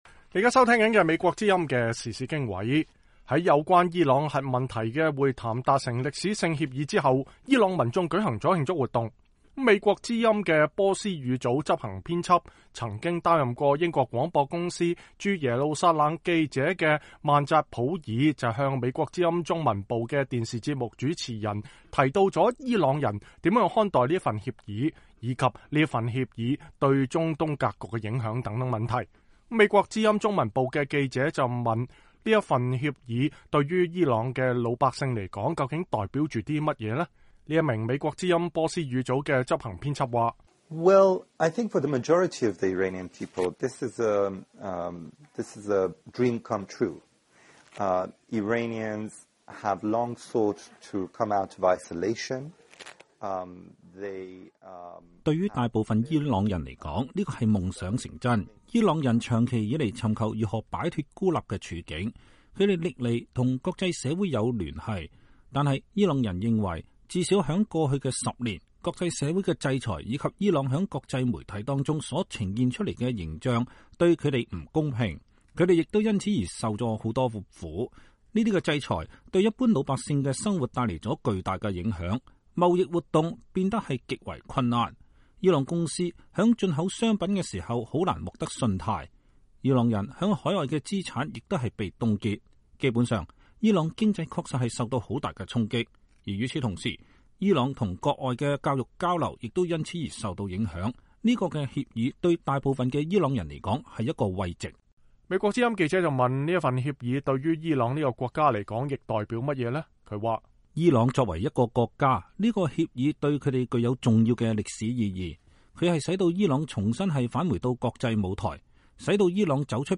VOA訪談：伊朗人怎麼看核協議？